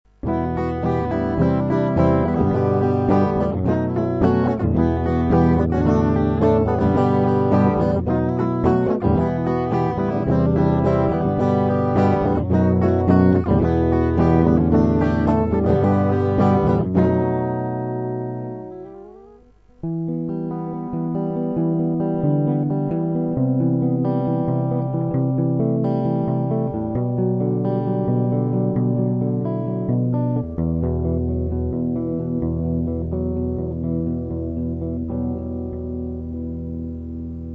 Вступление и проигрыш (Em - C - G - H7 - Em - D - G - H7)
(во вступ. перебором, в проигрышах - боем):